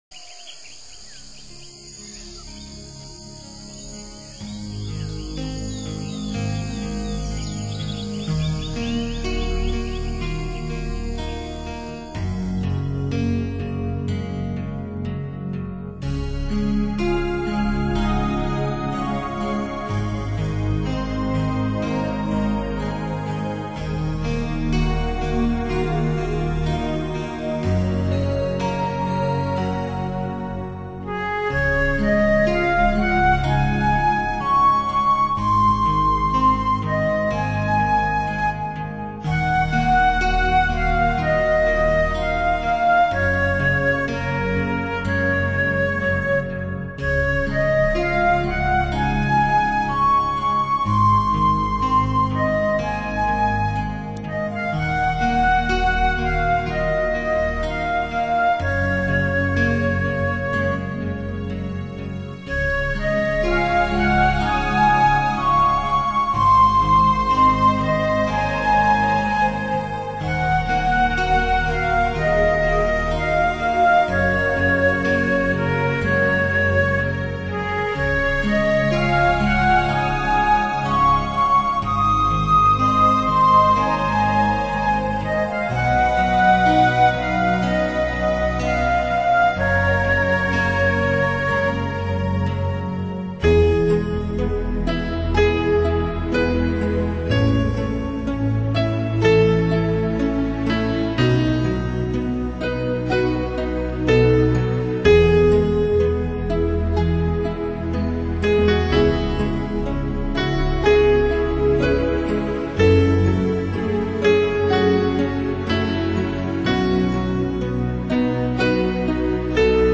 专辑语言：纯音乐
淡淡的音乐，自然的氛围